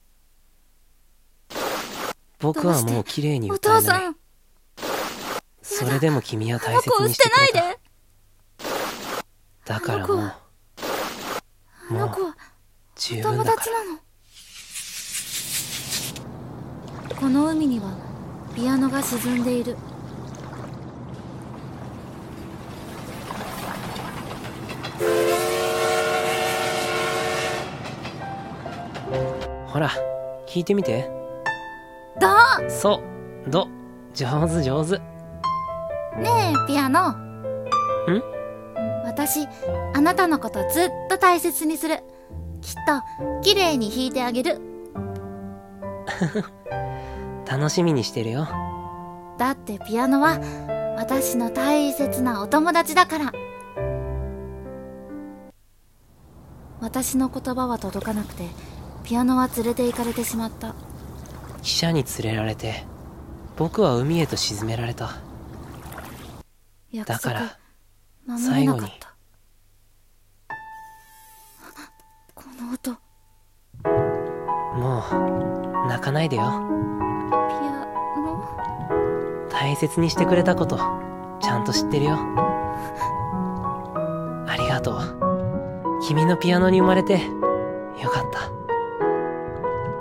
【二人声劇】海落とされたピアノ。